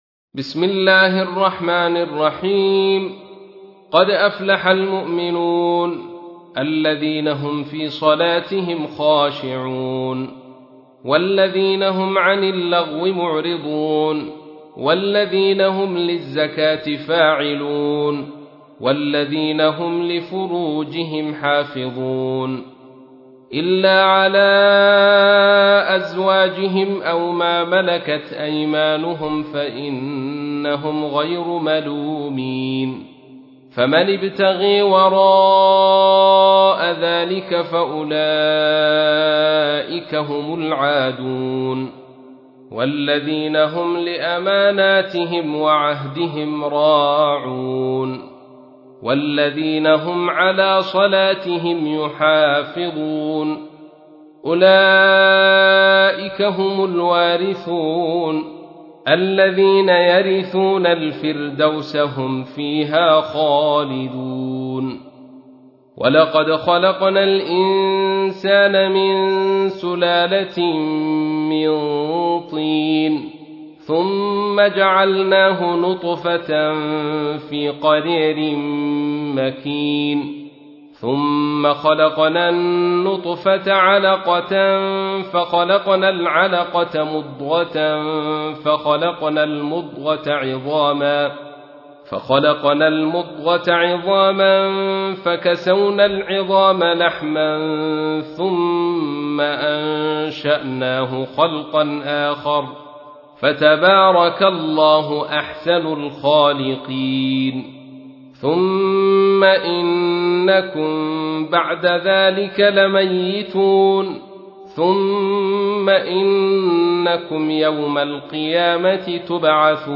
تحميل : 23. سورة المؤمنون / القارئ عبد الرشيد صوفي / القرآن الكريم / موقع يا حسين